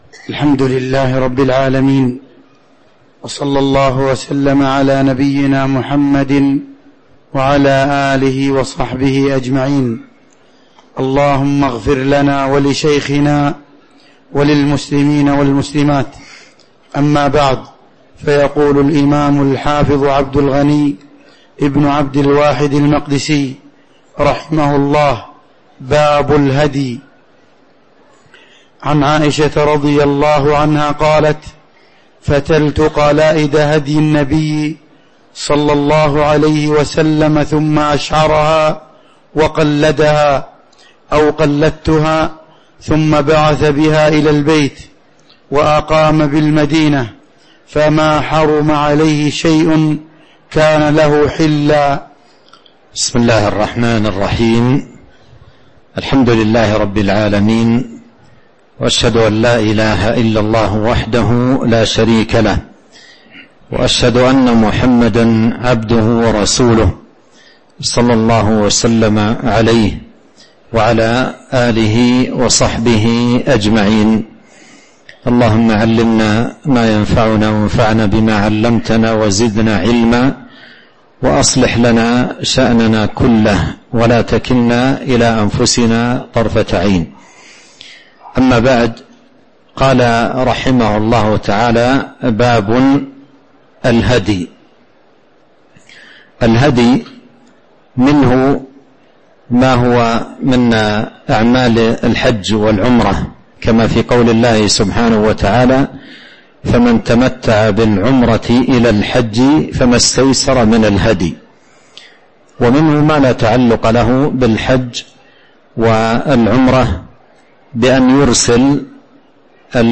تاريخ النشر ١٦ جمادى الآخرة ١٤٤٤ هـ المكان: المسجد النبوي الشيخ: فضيلة الشيخ عبد الرزاق بن عبد المحسن البدر فضيلة الشيخ عبد الرزاق بن عبد المحسن البدر باب الهدي (06)